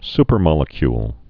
(spər-mŏlĭ-kyl)